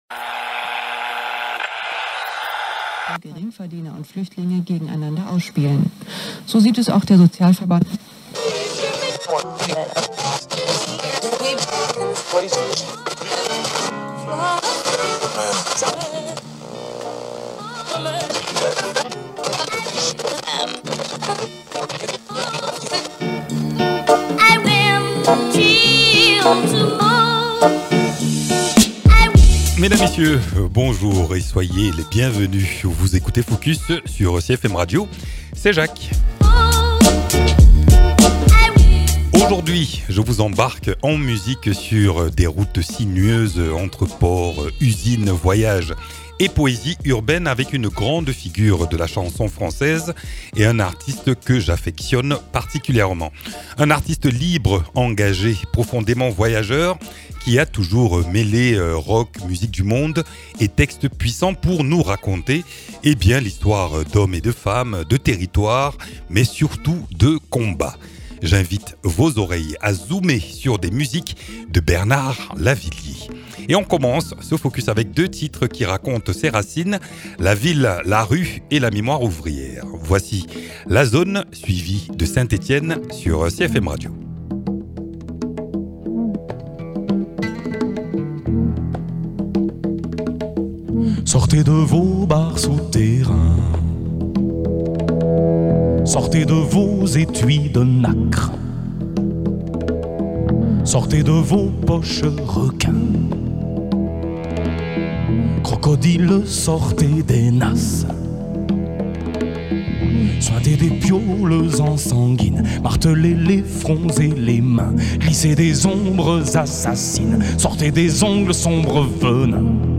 Nos oreilles s’envolent sur les traces d’un artiste voyageur, entre poésie brute, grooves métissés et récits de routes. Une immersion de 25 minutes pour découvrir ou redécouvrir une voix qui regarde le monde droit dans les yeux.